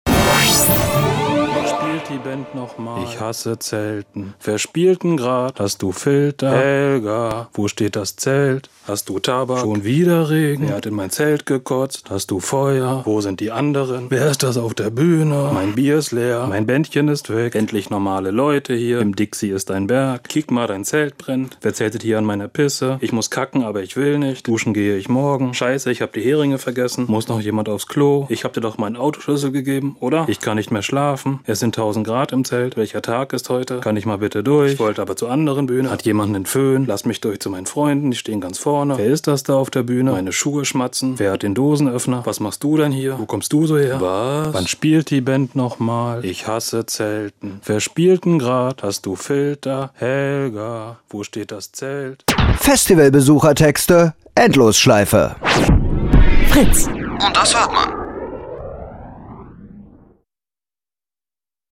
Endlosschleife "Festivaltexte" | Fritz Sound Meme Jingle